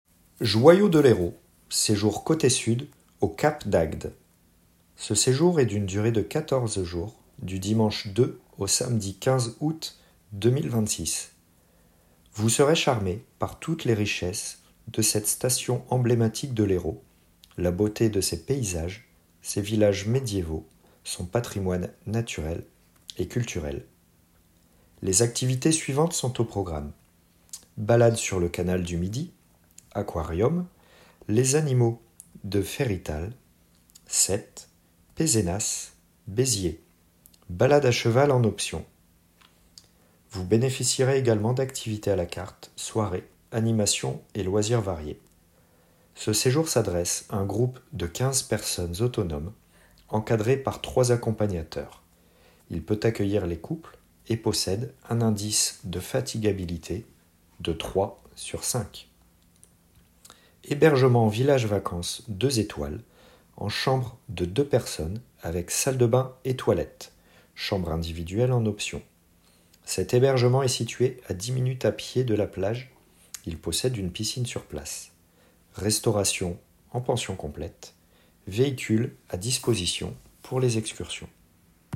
Présentation audio du séjour